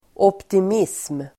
Ladda ner uttalet
Uttal: [åptim'is:m]
optimism.mp3